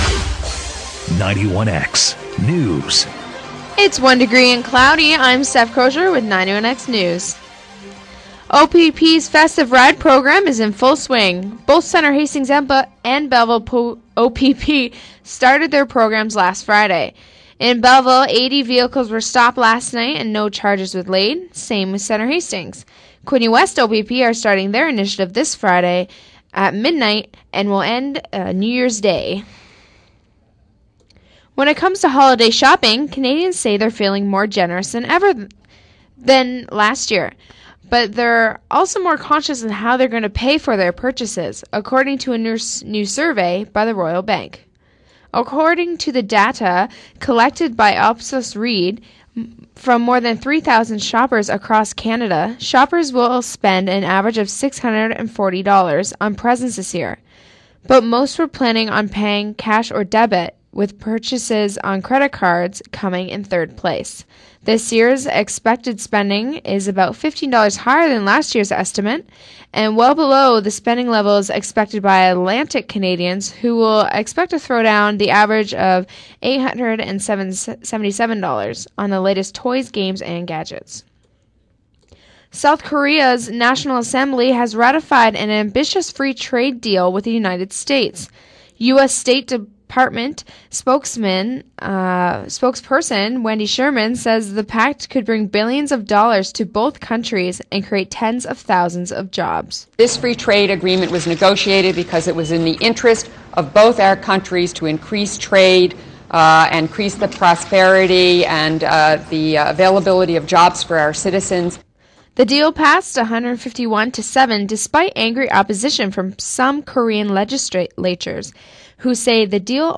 91x-news-nov.-22-2-pm.mp3